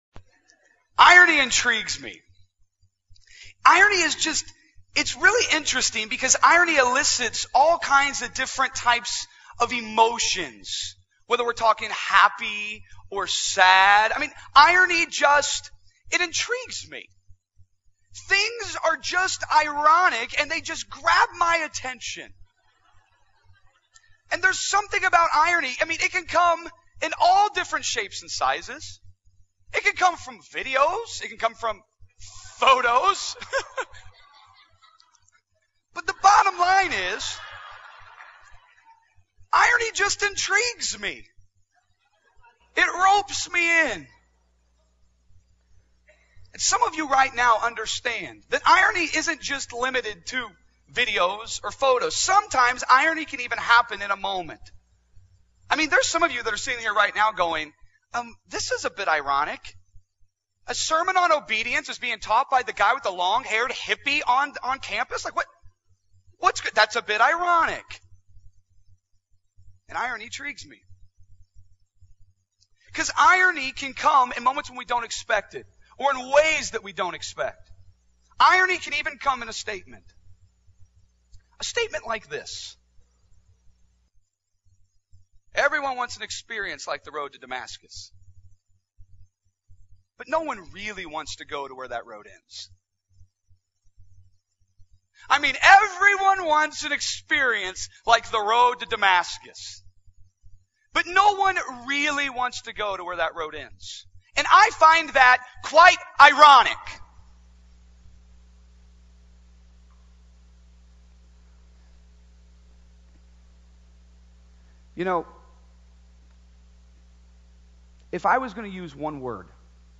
TITLE: The Road to Damascus (Acts 9) PLACE: Ozark Christian College (Joplin, MO) DESCRIPTION: Everyone wants an experience like the road to Damascus, but no one wants to go near where that road ends: obscurity. AUDIO: To listen to this sermon online, simply click this link.